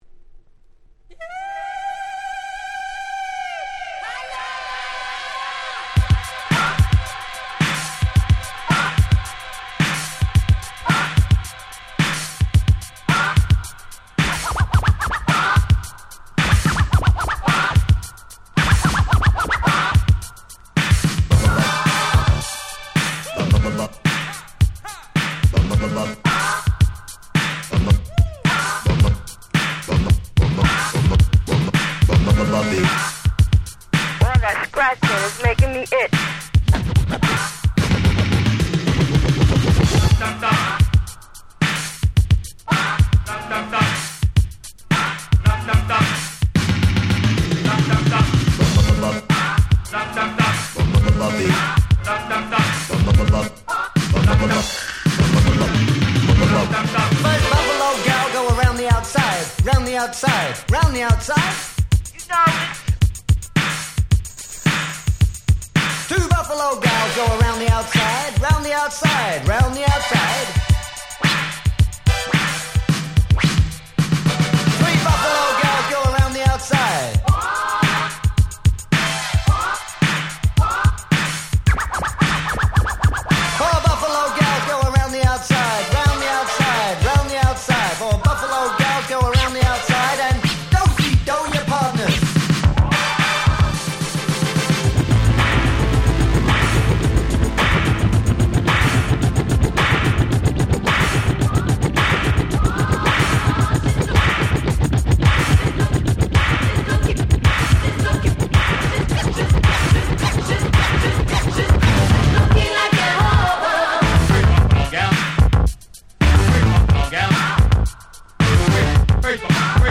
80's Old School オールドスクール